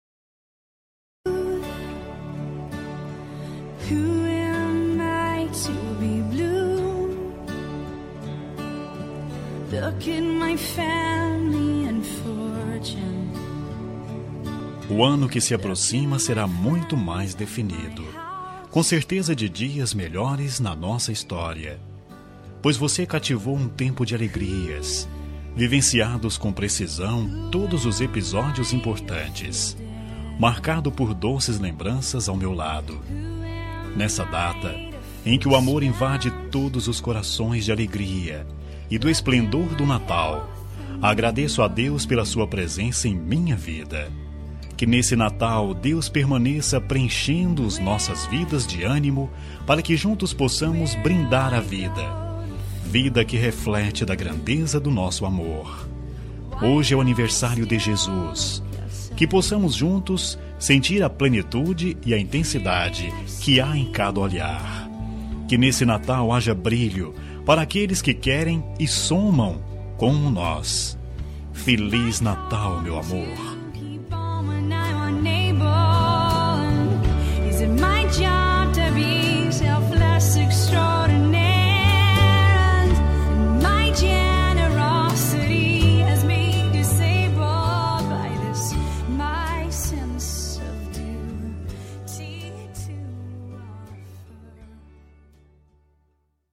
Natal Romântica – Voz Masculina – Cód: 34809